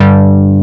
MINIMG BASS.wav